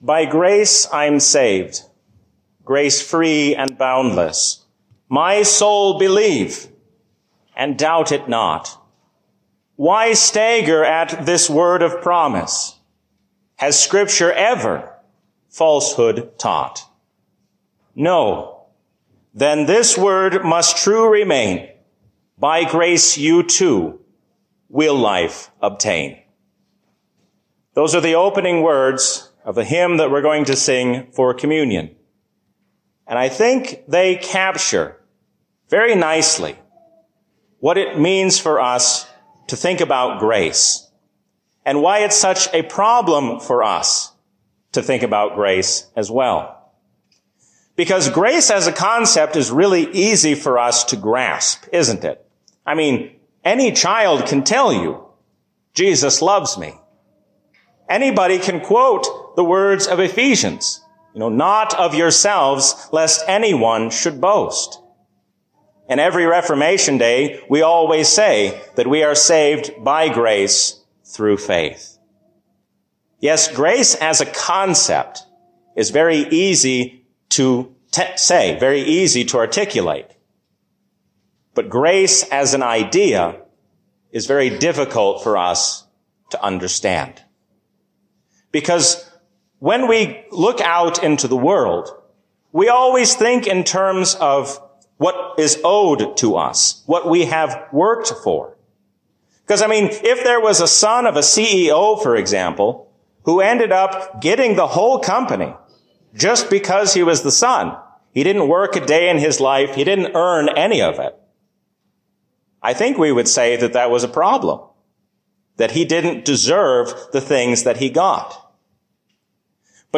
A sermon from the season "Trinity 2024." There is no reason to worry about Tuesday or any day to come when we remember that the Lord reigns as King forever.